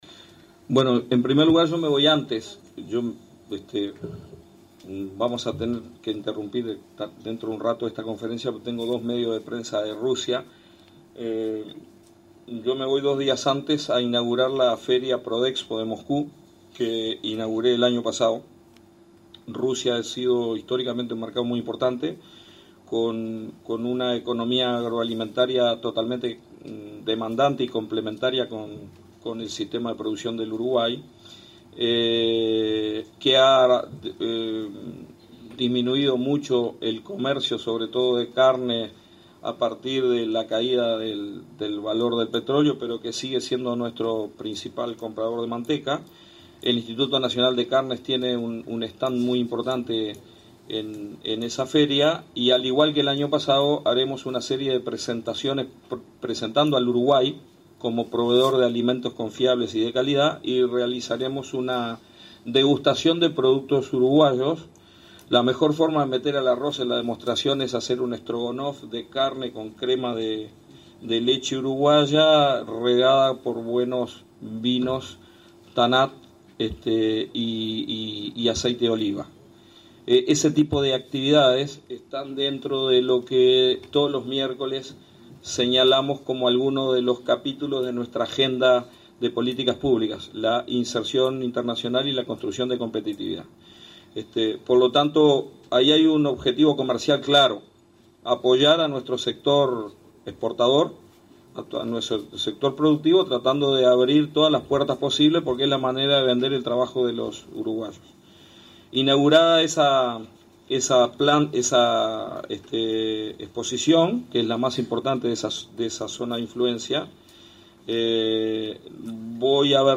El ministro de Ganadería Tabaré Aguerre, indicó en conferencia de prensa que Uruguay no solo necesita abrir mercados y bajar aranceles, sino que además debe construir una imagen en el mundo como proveedor confiable de productos de alta calidad”.
Ministro de Ganadería. mp3 5:35